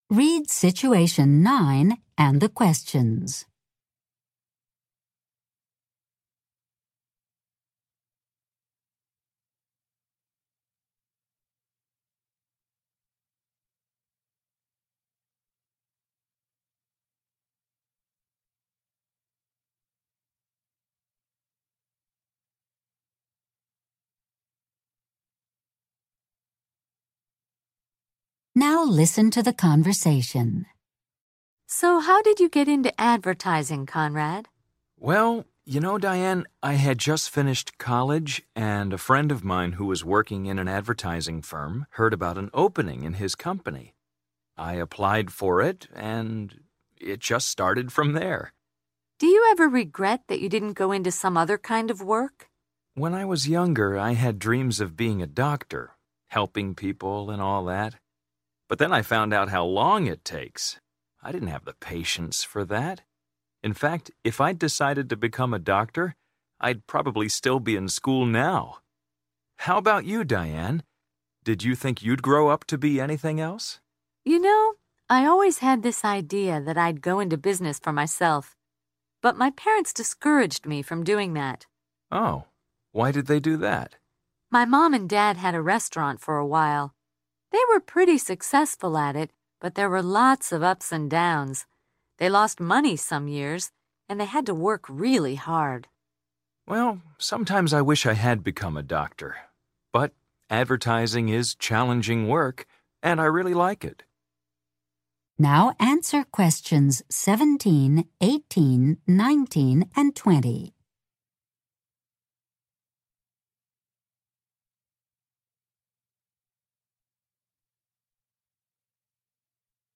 Situation 9: Diane and Conrad are talking about their careers. 9.